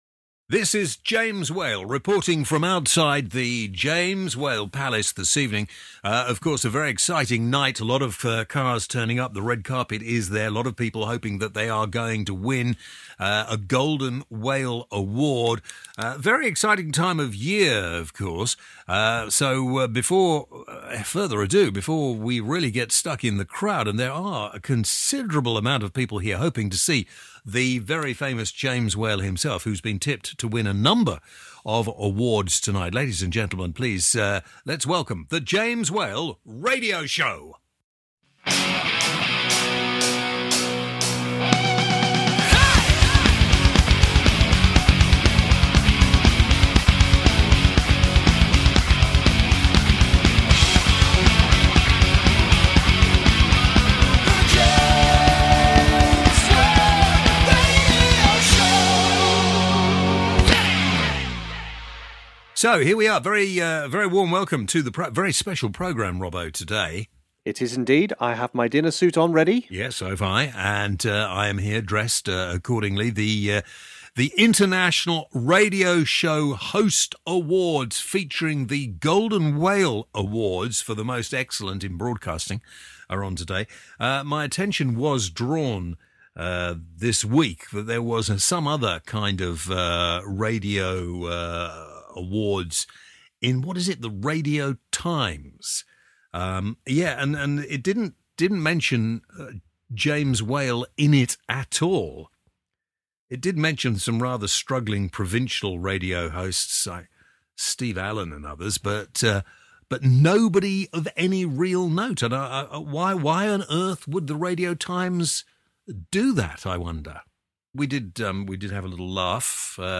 On the show: We go live this week to the National Whale Awards to find out who will win Best Radio Host and Best Radio Show.